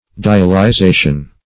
Dialyzation \Di`a*ly*za"tion\, n.